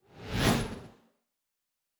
pgs/Assets/Audio/Sci-Fi Sounds/Movement/Fly By 01_4.wav at master
Fly By 01_4.wav